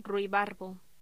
Locución: Ruibarbo
Sonidos: Voz humana